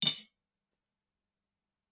餐具的声音 " 小勺子8
Tag: 餐具